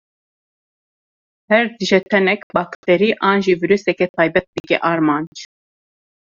Wekî (IPA) tê bilêvkirin
/ɑːɾˈmɑːnd͡ʒ/